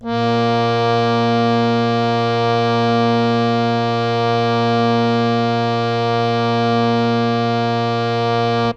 harmonium